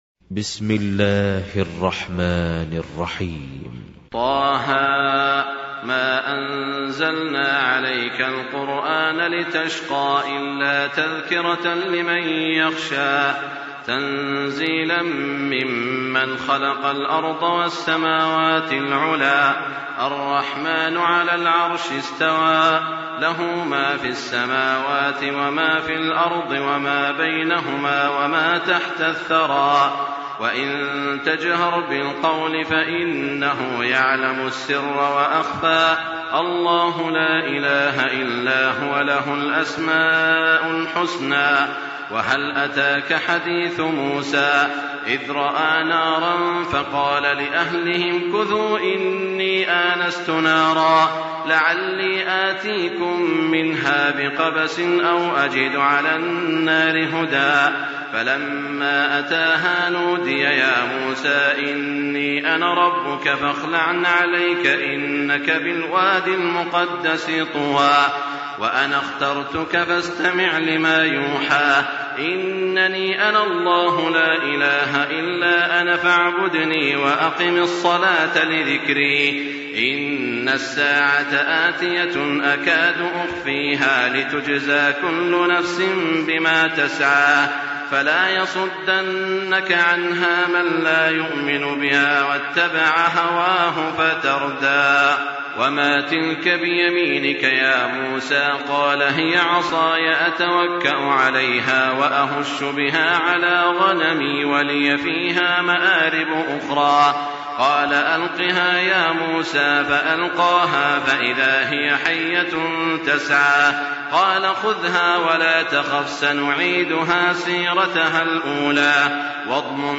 تراويح الليلة الخامسة عشر رمضان 1424هـ سورة طه كاملة Taraweeh 15 st night Ramadan 1424H from Surah Taa-Haa > تراويح الحرم المكي عام 1424 🕋 > التراويح - تلاوات الحرمين